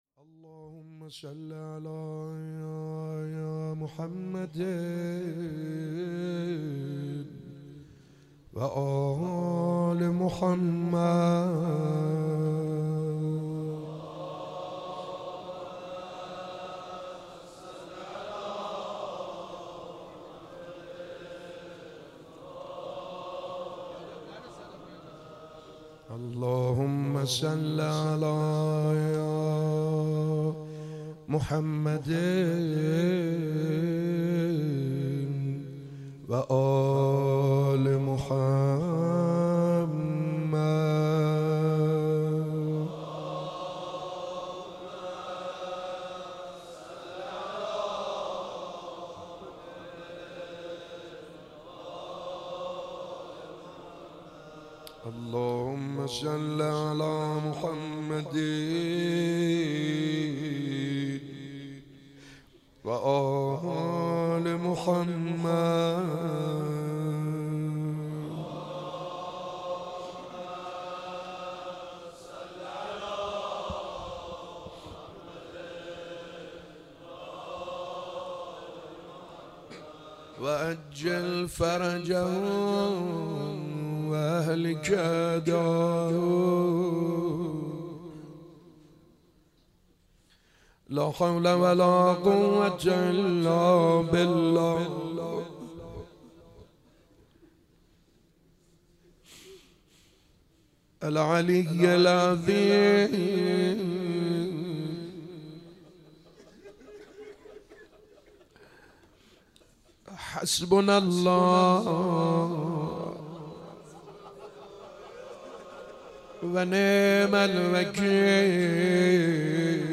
روز عرفه 9 شهریور - مناجات - نبود و نیست از تو
مداحی